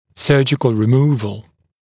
[‘sɜːʤɪkl rɪ’muːvl][‘сё:джикл ри’му:вл]хирургическое удаление